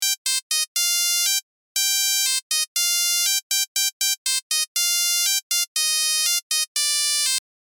Old-mobile-phone-ringtone-call-ring-loop.mp3